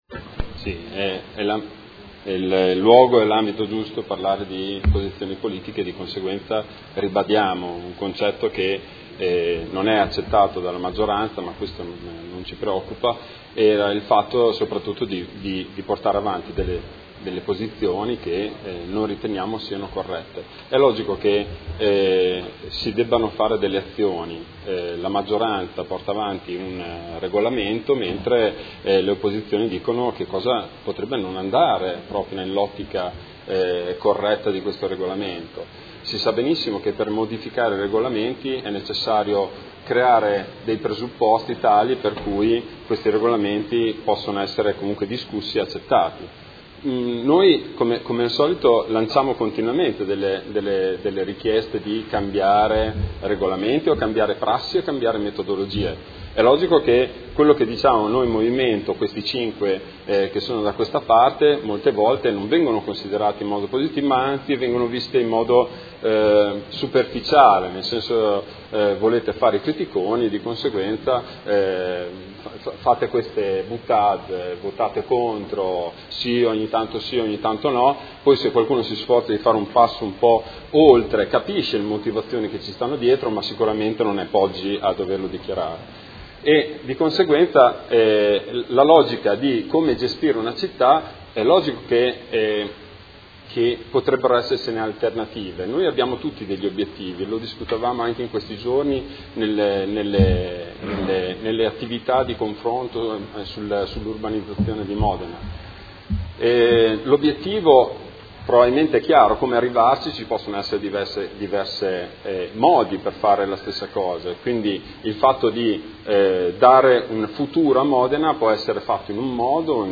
Marco Bortolotti — Sito Audio Consiglio Comunale